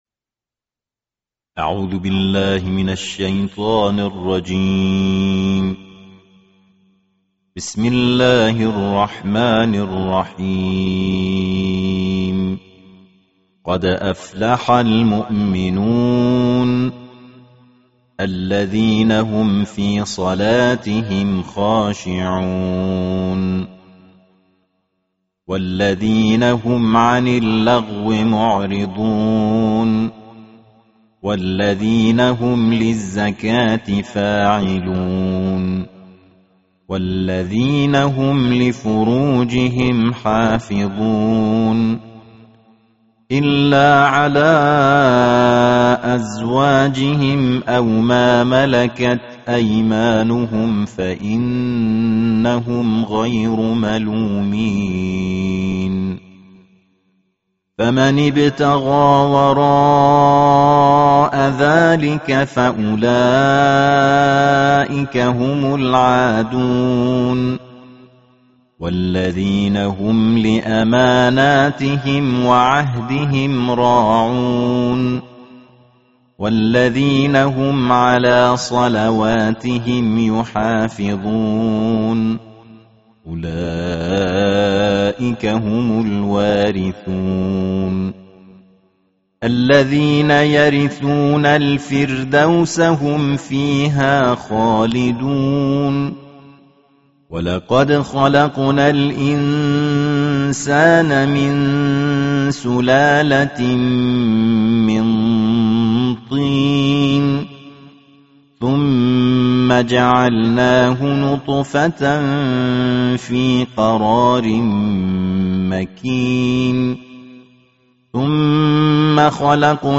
تلاوت ترتیل جزء هجدهم قرآن
این مدرس قرآن در این تلاوت جدید، سعی داشته تا در کنار اجرای مقامات اصیل عربی، از نغمات فارسی و کشورهای همسایه جهت تلمیح الحان بهره‌مند شود.